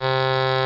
Crumhorn Tenor Sound Effect
Download a high-quality crumhorn tenor sound effect.
crumhorn-tenor.mp3